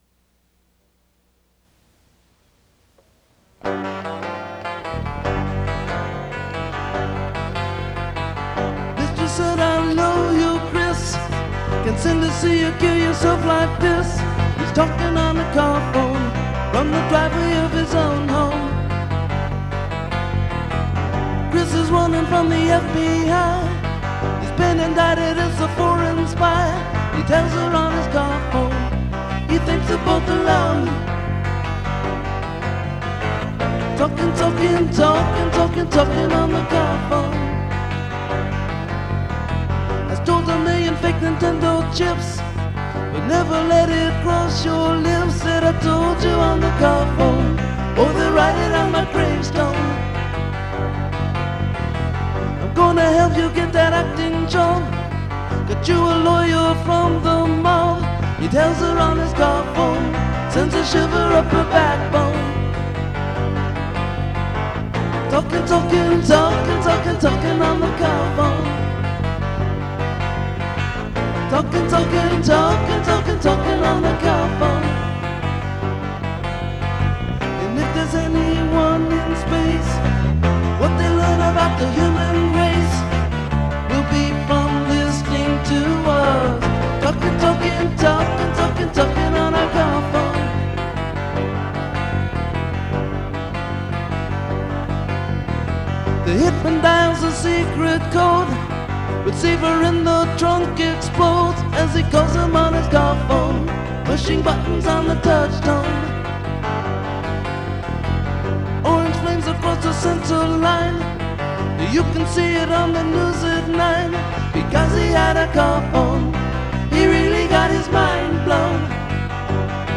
Category Rock/Pop
Studio/Live Studio
12-string guitar and vocals
bass and backing vocals